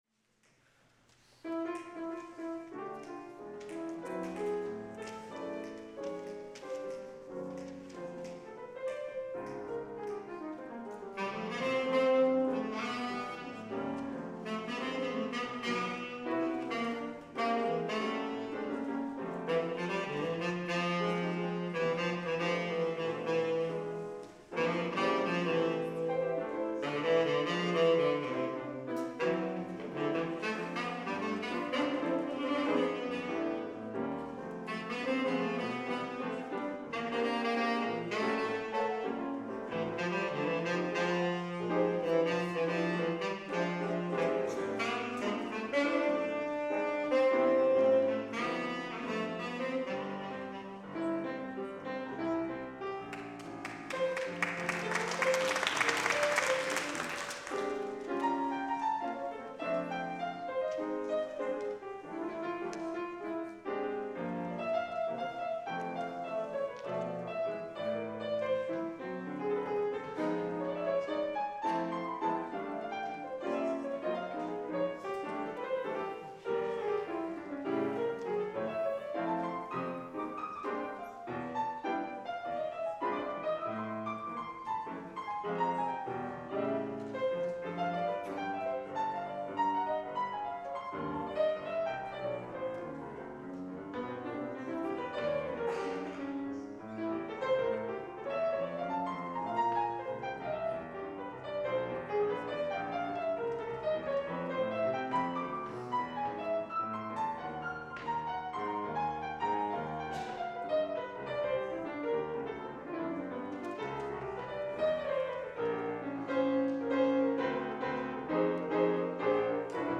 Here is a recording made at a concert at Eastern Oregon University
jazz pianist